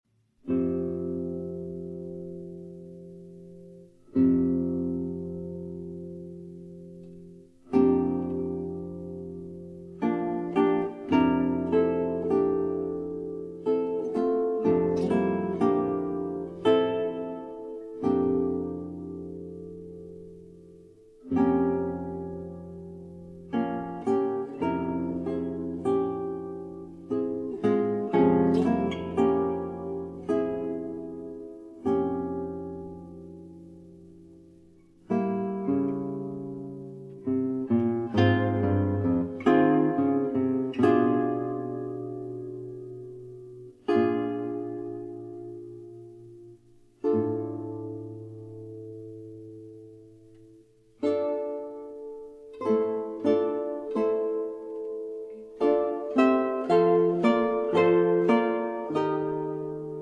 3 Guitars